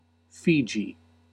Ääntäminen
IPA : /ˈfiː.dʒiː/ IPA : /ˈfi.dʒi/